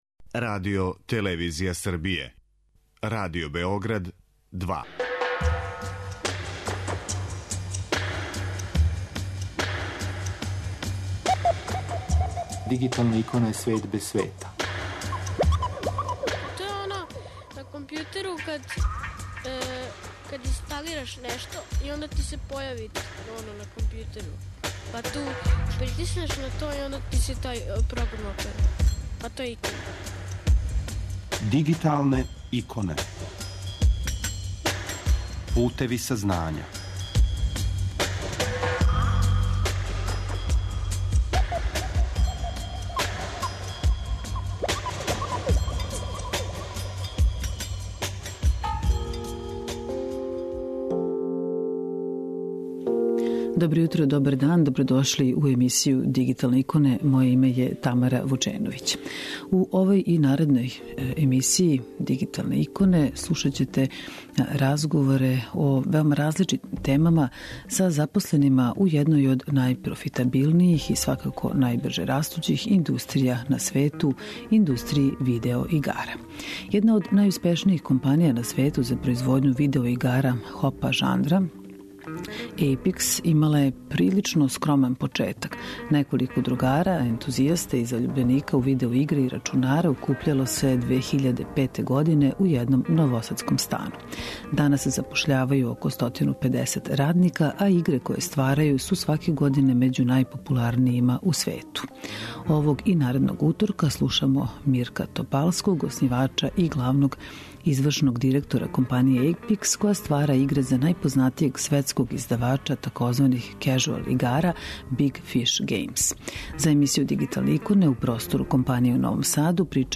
У овој емисији и наредним издањима 'Дигиталних икона' слушаћете разговоре о различитим темама са запосленима у једној од најпрофитабилнијих и најбрже растућих индустрија на свету - индустрији видео-игара.